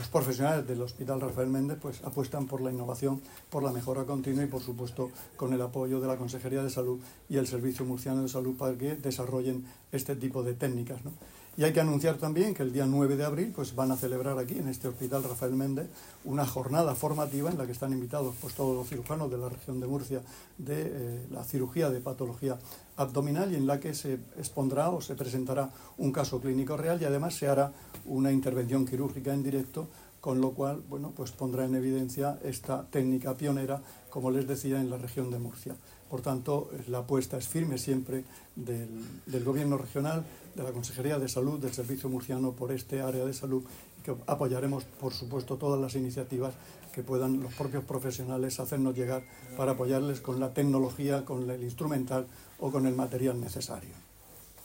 Sonido/ Declaraciones del consejero de Salud, Juan José Pedreño, sobre la técnica pionera de cirugía abdominal compleja que realiza el hospital Rafael Méndez de Lorca.